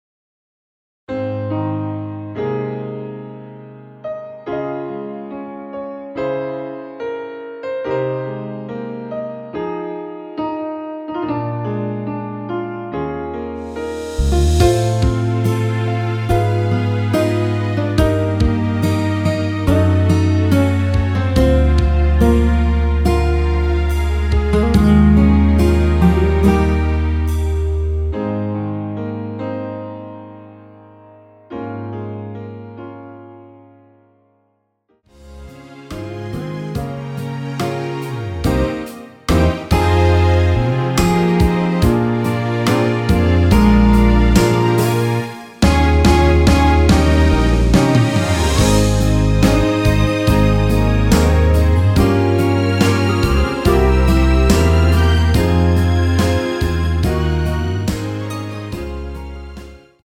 원키에서(+3)올린 MR입니다.
Ab
앞부분30초, 뒷부분30초씩 편집해서 올려 드리고 있습니다.
중간에 음이 끈어지고 다시 나오는 이유는